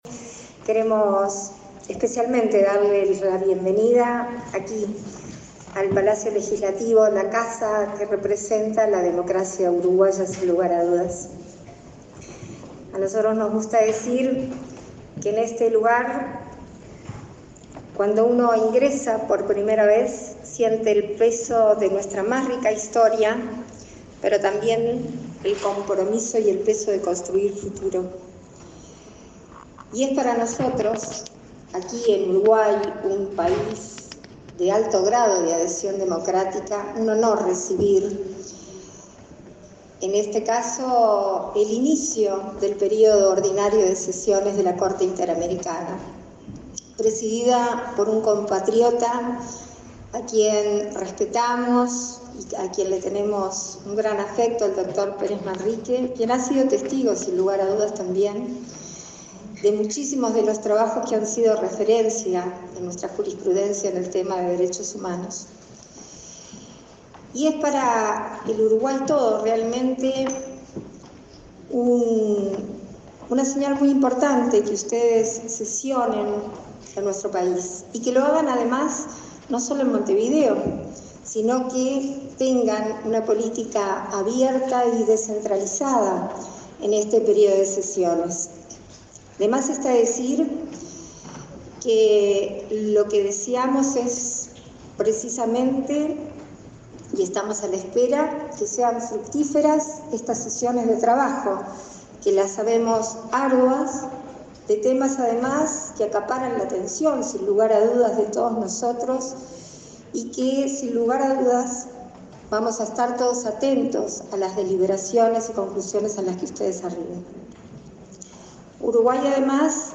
Palabras de autoridades en el Palacio Legislativo
Palabras de autoridades en el Palacio Legislativo 11/10/2022 Compartir Facebook X Copiar enlace WhatsApp LinkedIn La vicepresidenta de la República, Beatriz Argimón, y el canciller, Francisco Bustillo, participaron en la ceremonia de instalación en Uruguay del 153.° Período Ordinario de Sesiones de la Corte Interamericana de Derechos Humanos.